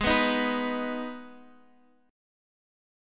Listen to Abm strummed